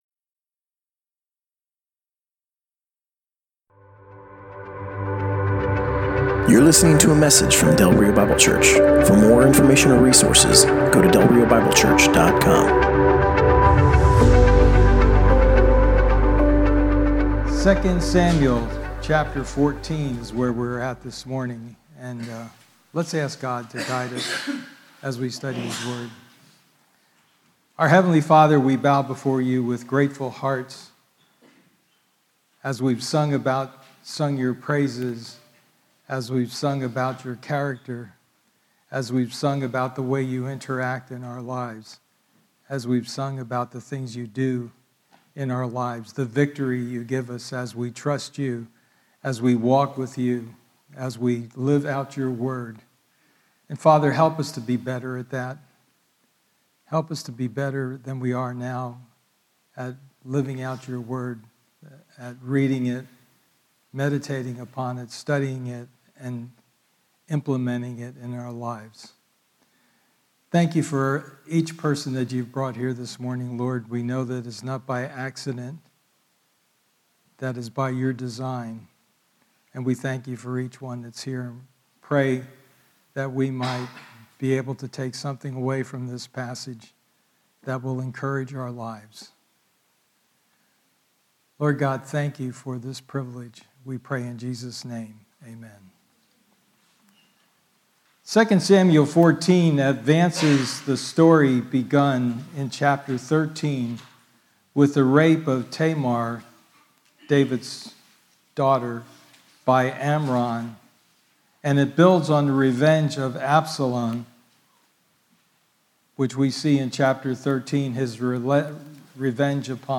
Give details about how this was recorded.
Passage: 2 Samuel 14: 1-24 Service Type: Sunday Morning